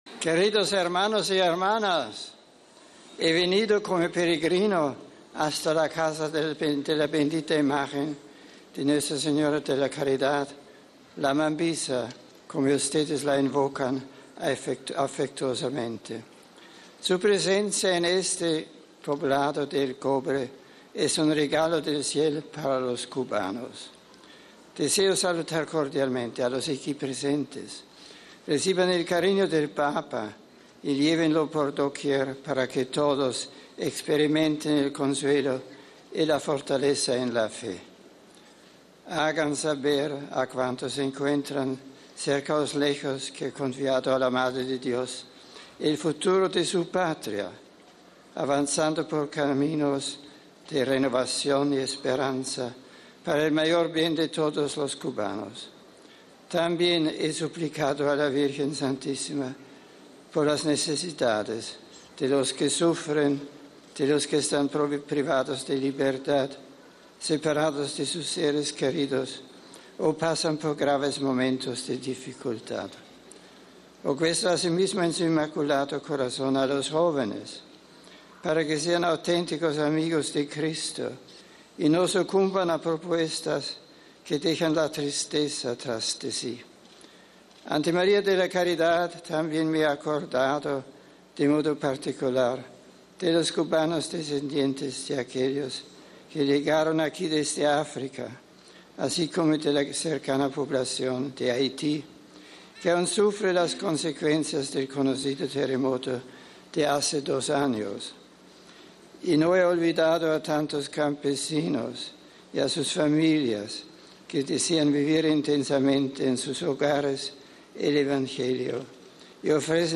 Texto y audio completo del saludo de Benedicto XVI (Audio) RealAudio